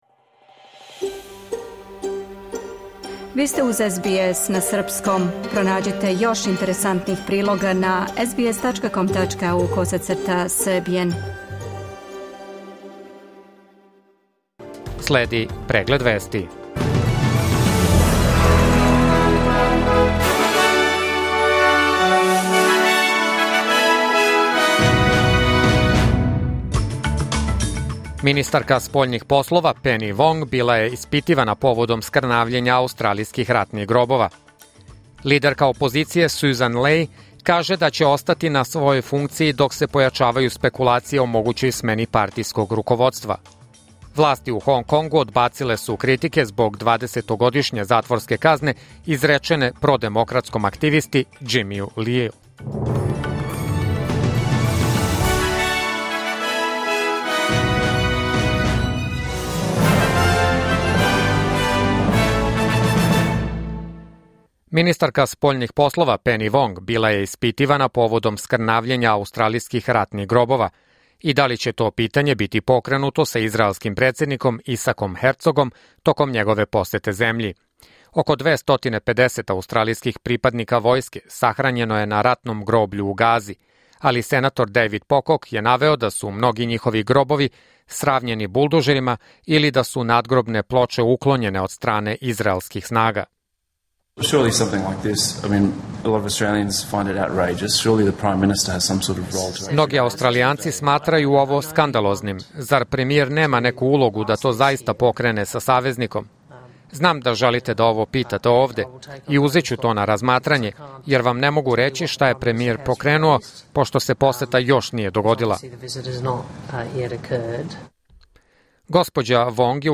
Serbian News Bulletin Source: SBS / SBS Serbian